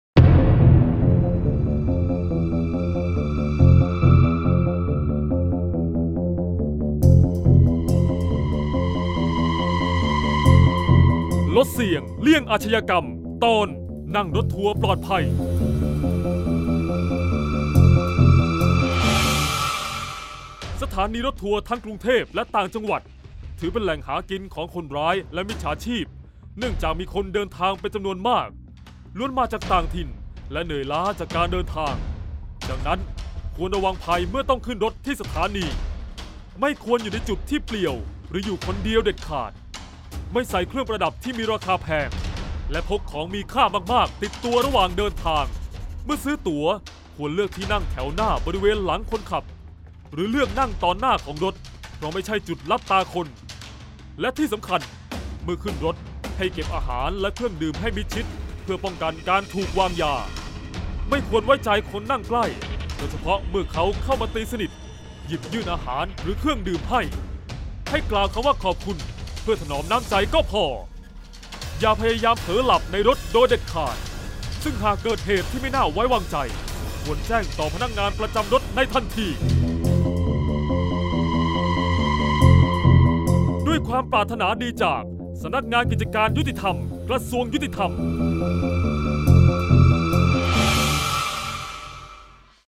เสียงบรรยาย ลดเสี่ยงเลี่ยงอาชญากรรม 23-ขึ้นรถทัวร์ปลอดภัย